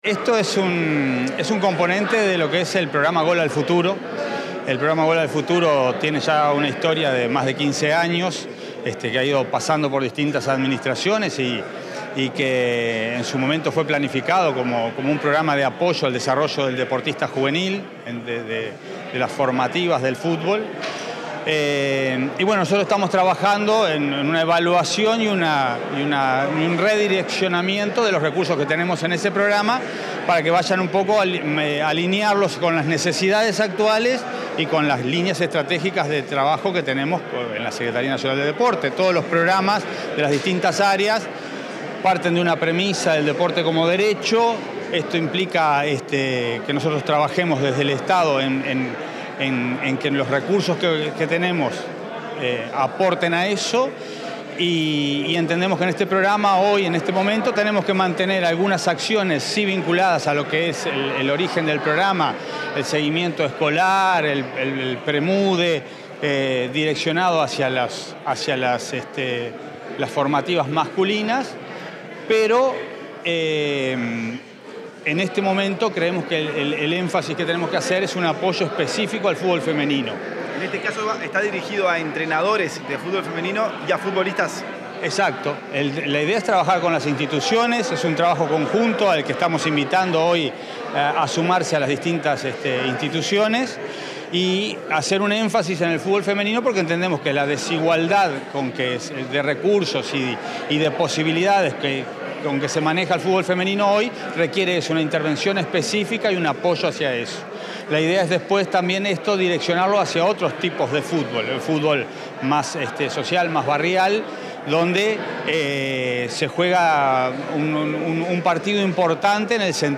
Declaraciones del secretario nacional del Deporte, Alejandro Pereda
El secretario nacional del Deporte, Alejandro Pereda, dialogó con la prensa tras participar en el lanzamiento del programa Campus Gol.